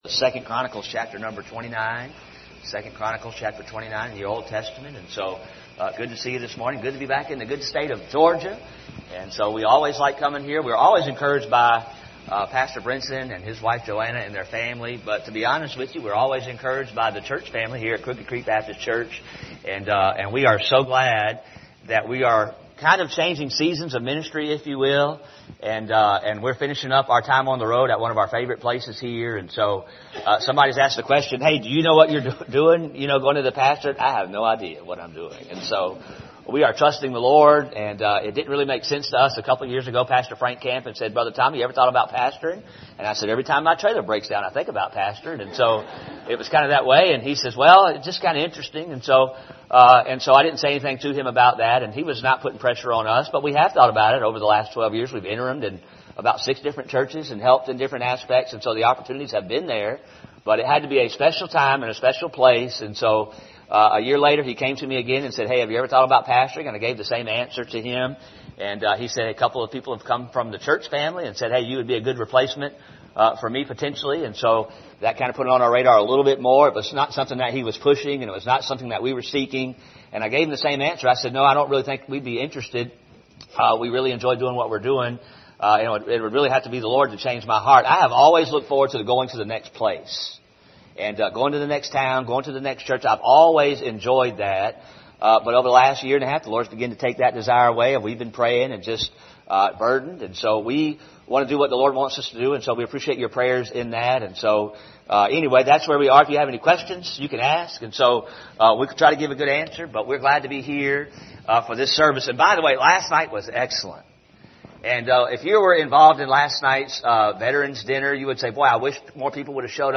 Passage: 2 Chronicles 29 Service Type: Sunday Morning View the video on Facebook Topics